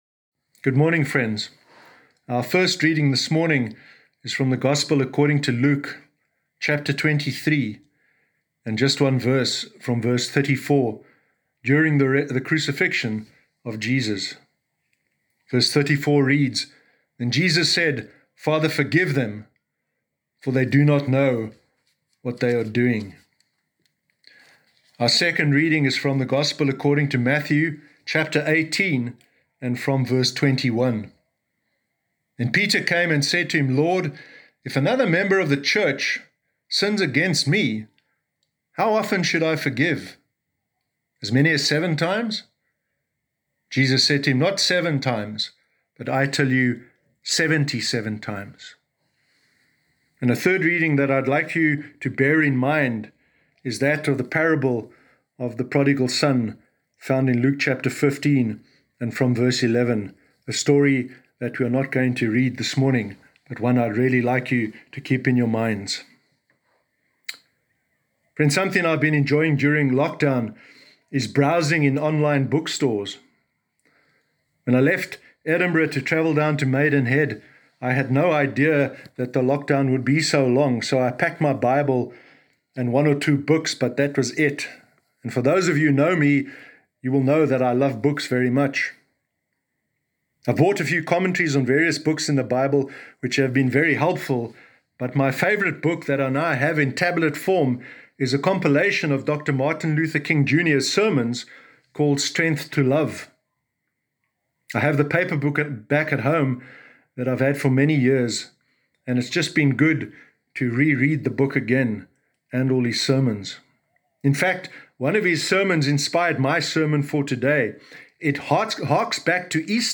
Sermon Sunday 12 July 2020